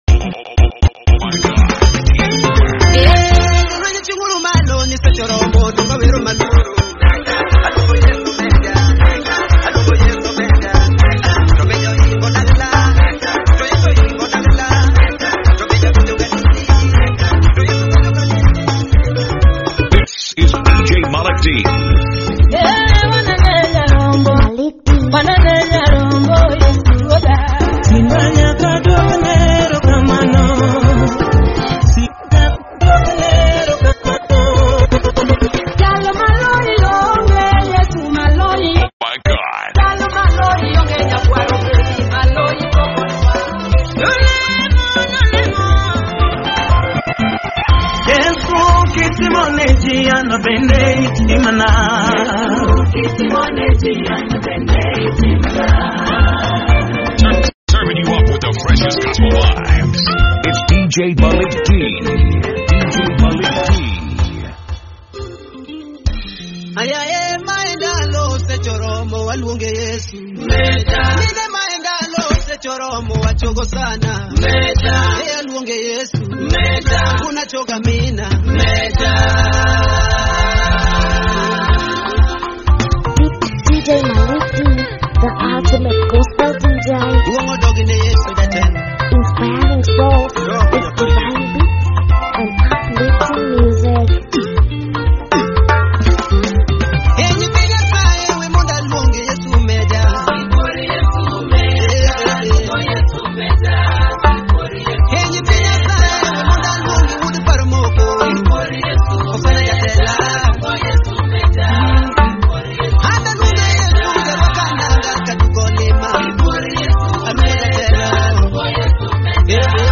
Dj Mix